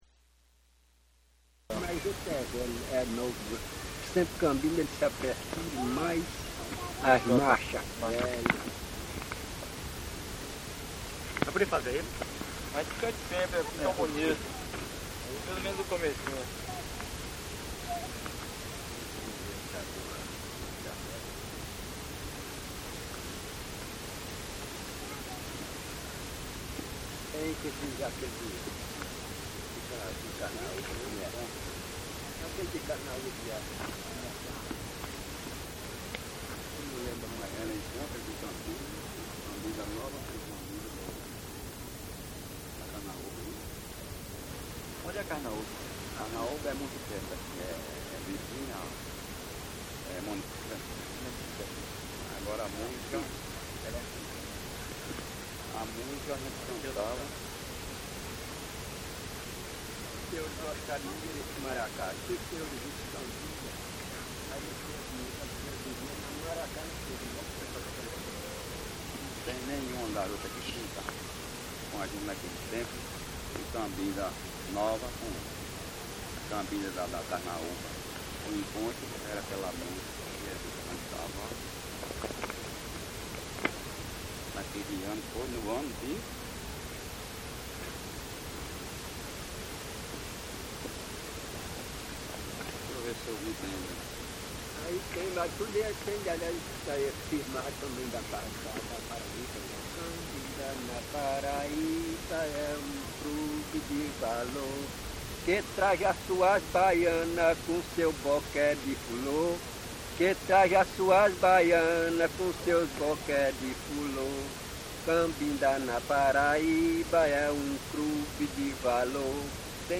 Formados em duas alas [...] a família real no centro, na frente o embaixador, o grupo sai às ruas de Lucena dançando e cantando num ritmo marcado pelos zabumbas e maracás .
Itaperoá Entrevista coletiva